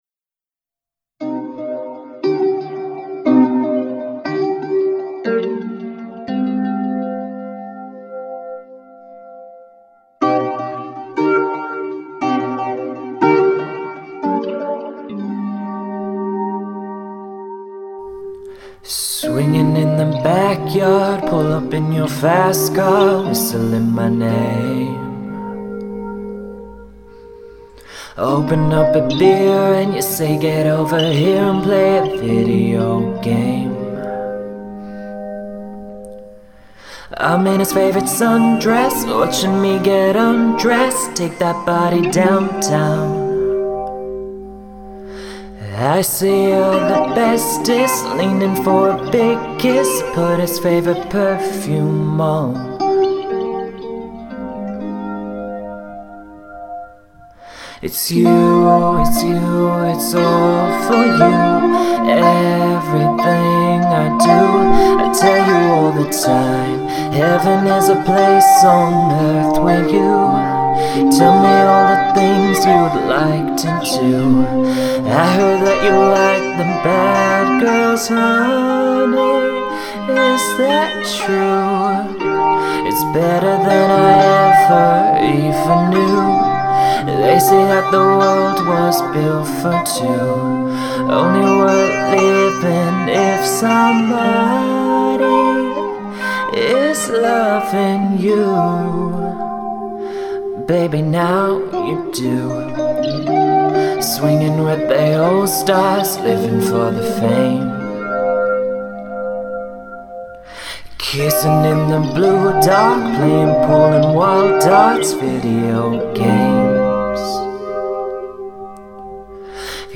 Just wanted to share this vocal + instrumental with you, would be awesome if someone could give it a shot. Pretty happy with how the instrumental turned out but the vocal is a bit rough, recorded it with a Blue Snowball and it picked up some background noise since I live in a busy building.